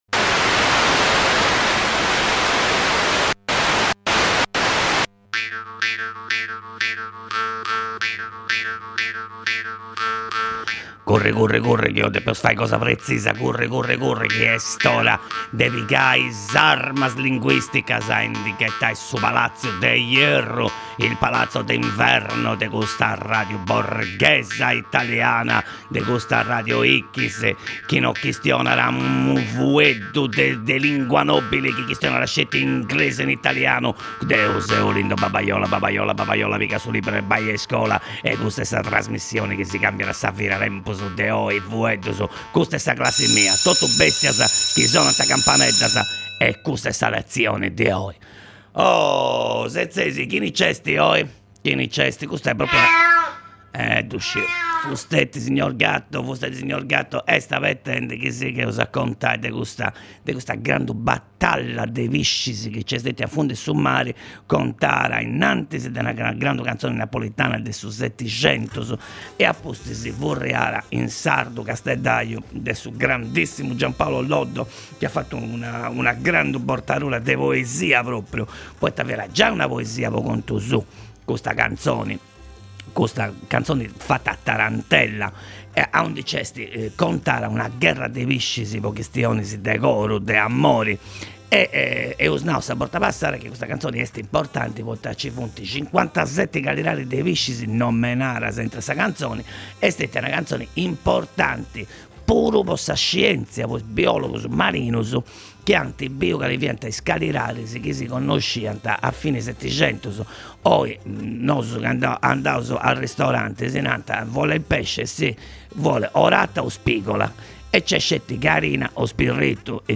Professor Olindo Babaiola dogna di’ ìntrat a fura in is undas de Radio X po fai scola de sardu.